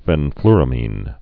(fĕn-flrə-mēn)